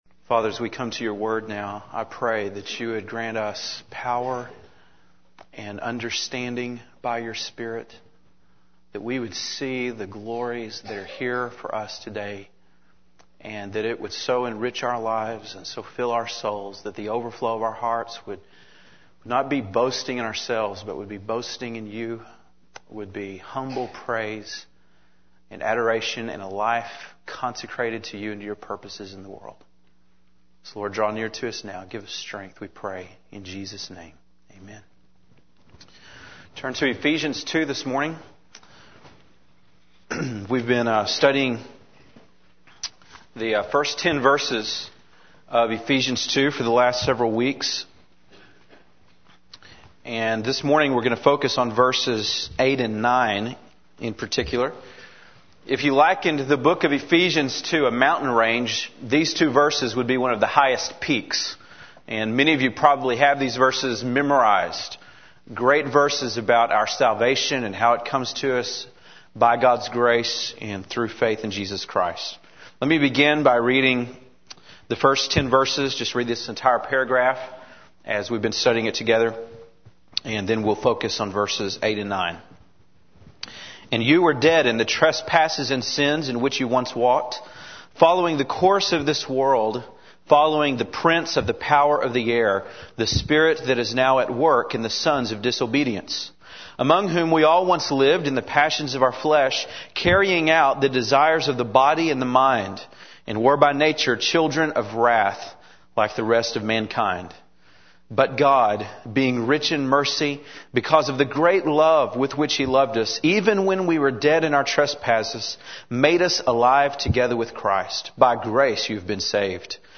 December 5, 2004 (Sunday Morning)